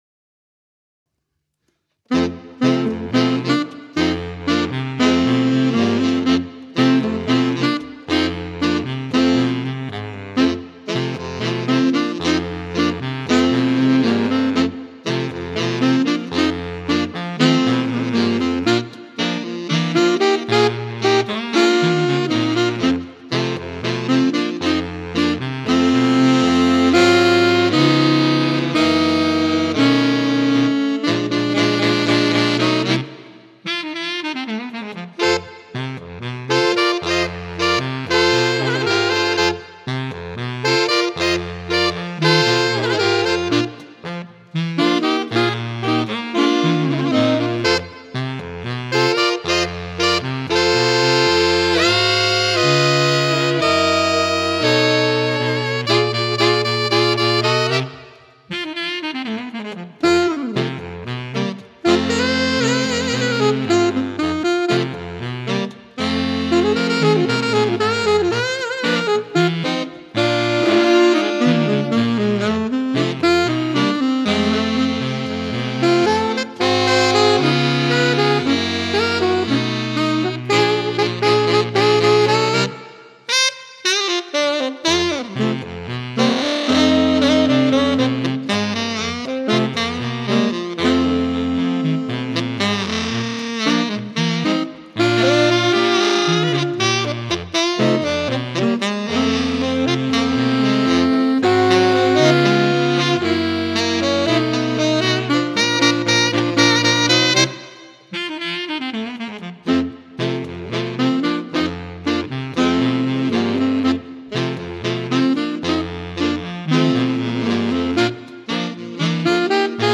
Ranges: Alto 1: D3. Tenor: D3. Baritone: D1